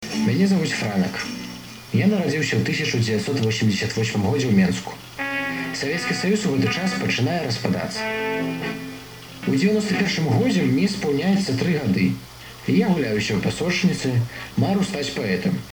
Yeah, sounds very much like Russian.